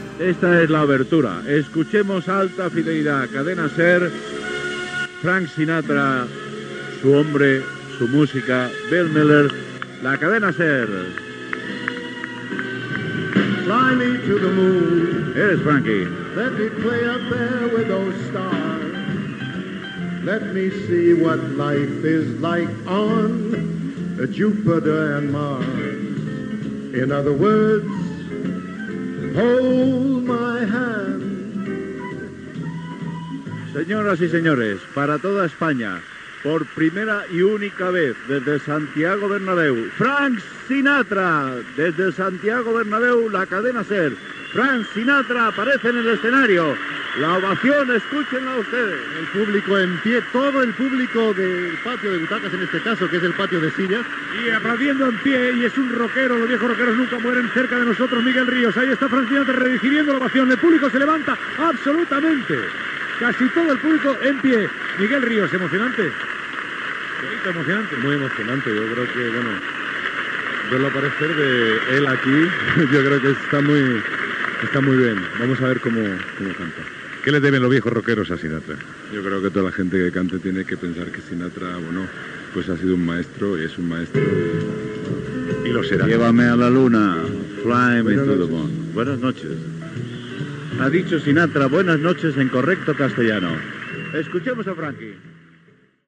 Transmissió de l'actuació de Frank Sinatra a l'Estadio Santiago Bernabeu de Madrid,
Narració de l'inici del concert amb la sortida de Frank Sinatra a l'escenari i l'opinió del cantant Miguel Ríos que assisteix com a públic.